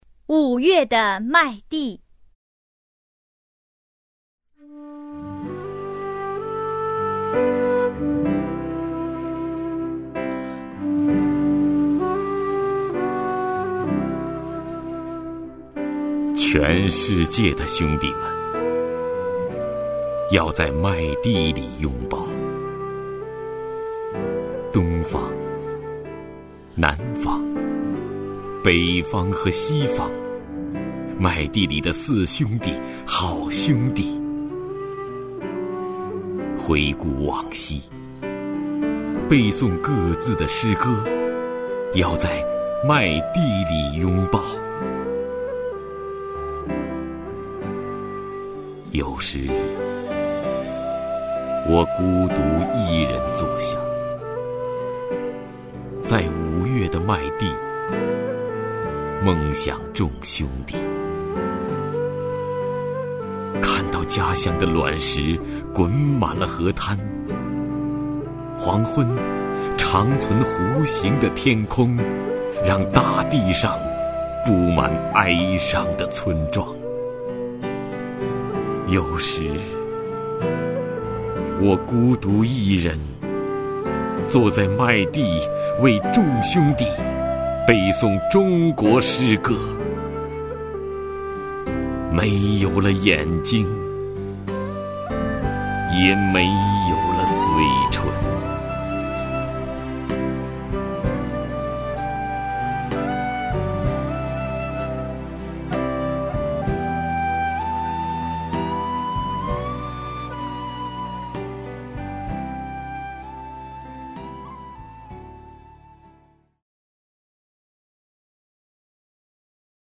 徐涛朗诵：《五月的麦地》(海子)
名家朗诵欣赏 徐涛 目录